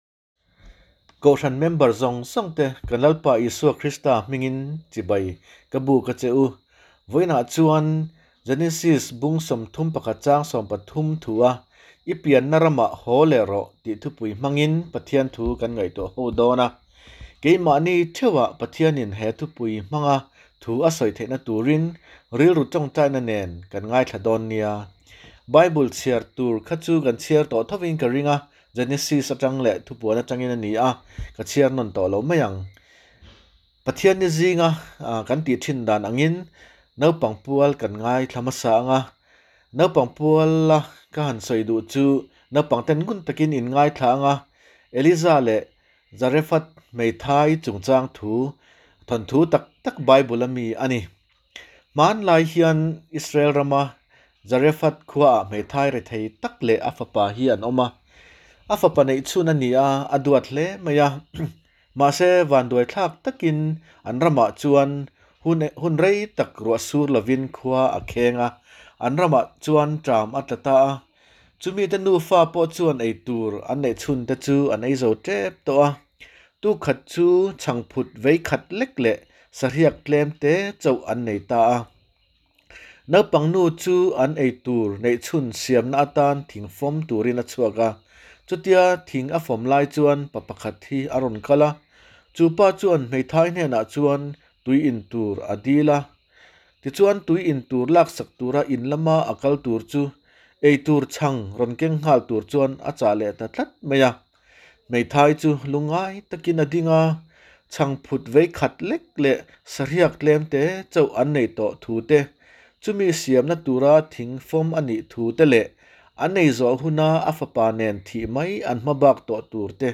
THUCHAH